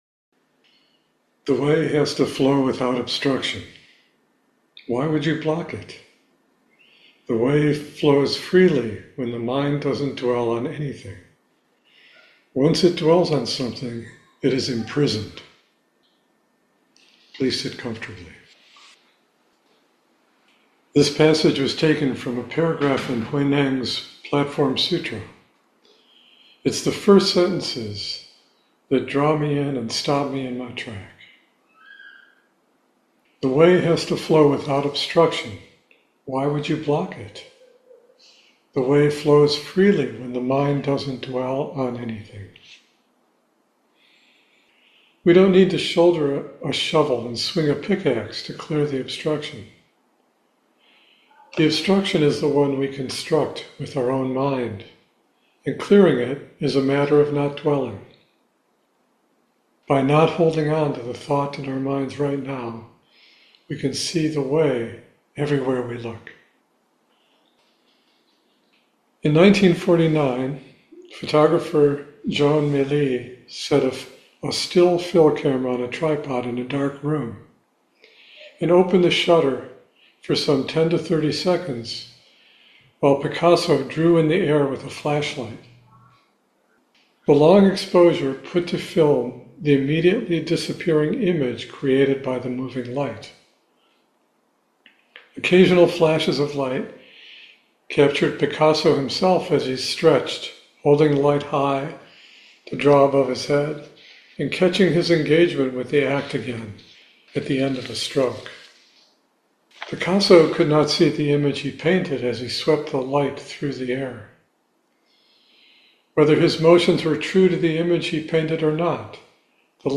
Listen to the talk …The Way has to flow without obstruction.